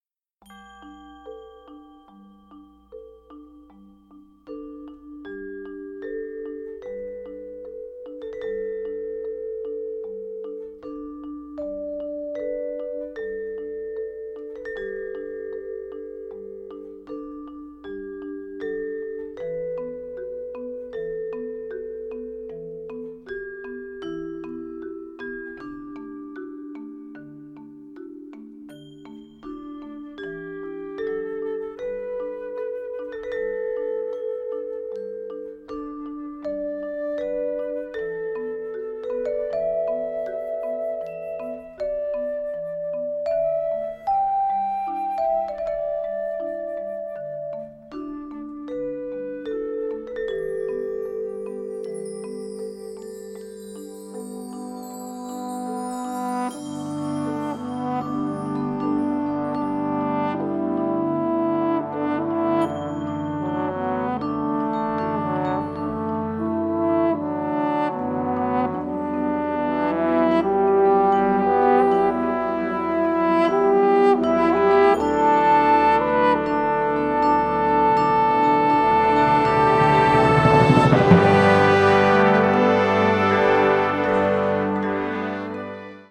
Catégorie Harmonie/Fanfare/Brass-band
Sous-catégorie Musique de concert
Instrumentation Ha (orchestre d'harmonie)
une mélodie pentatonique